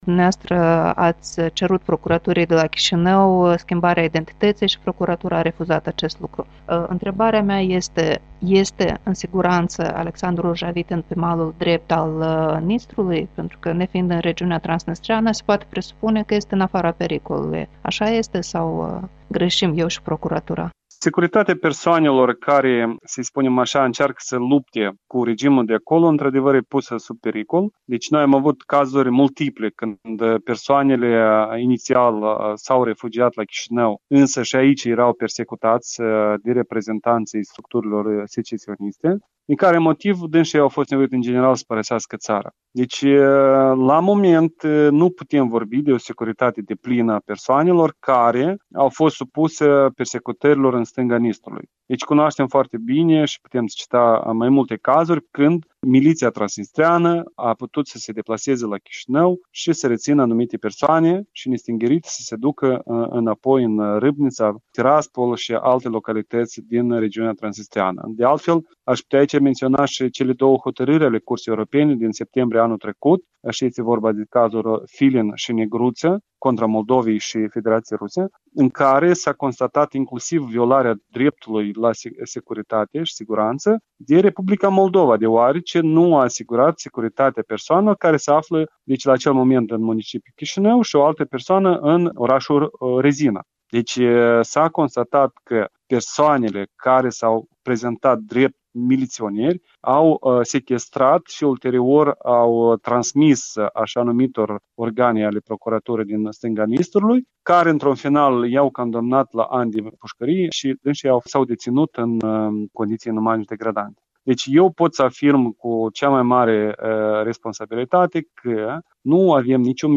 avocat